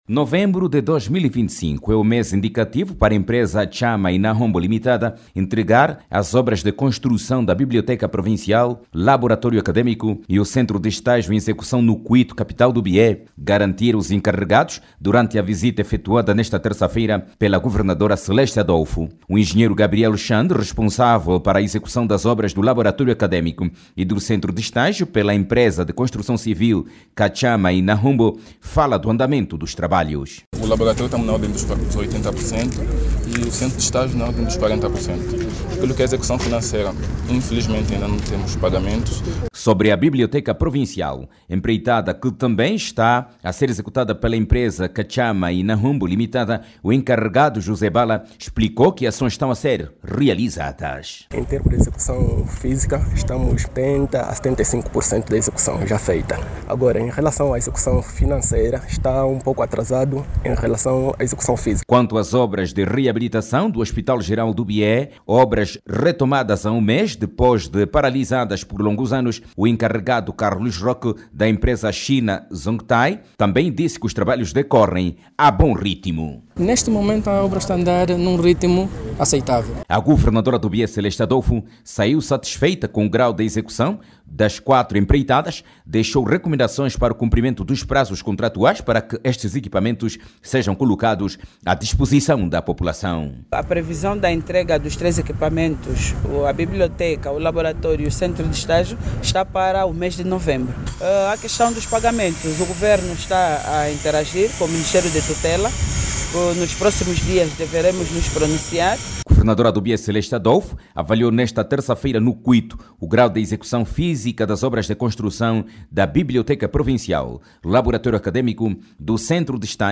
A Governadora do Bié realizou ontem, quarta-feira(09), uma visita de constatação a obras sociais que estão em curso naquela provinvia. Celeste Adolfo, recebeu das empreiteiras garantias de cumprimento dos prazos contratuais, e que as obras terão a qualidade esperada. Clique no áudio abaixo e ouça a reportagem